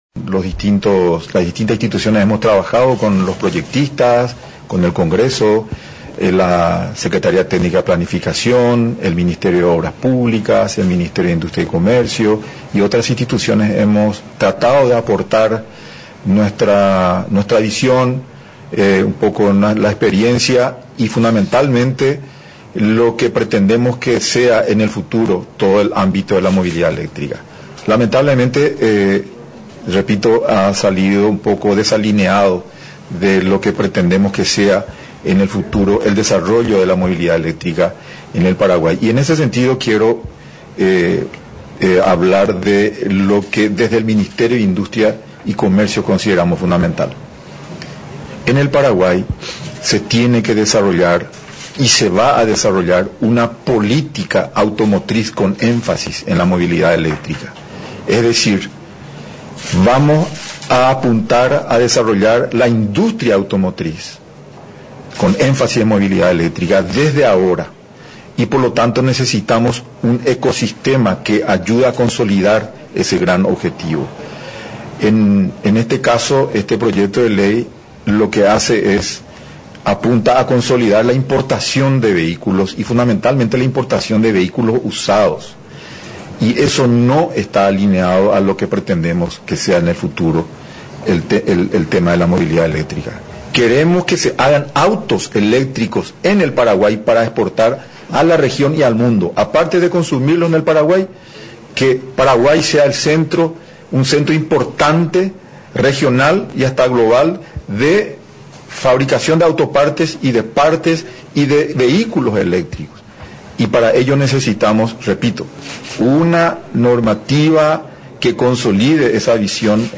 Paraguay quiere ser centro importante de fabricación de vehículos eléctricos, por este motivo, el Ejecutivo veta el Proyecto de Ley sancionado por el Congreso. En agosto próximo se presentará nueva propuesta en el rubro, dijo en la jornada de hoy el Ministro de Industria y Comercio, Luis Alberto Castiglioni.